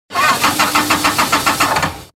Car Engine Failure Sound Effect
Harsh grinding noise when turning the key – car won’t start. Car ran out of fuel. Car sounds.
Car-engine-failure-sound-effect.mp3